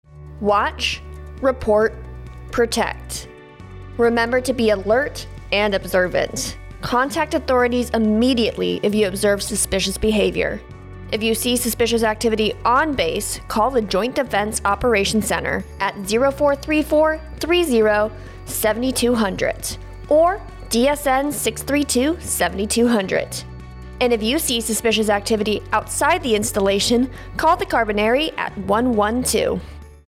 CarabinieriRadio SpotJDOCJoint Defense Operation Center